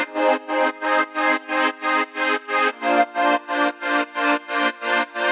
GnS_Pad-MiscB1:8_90-C.wav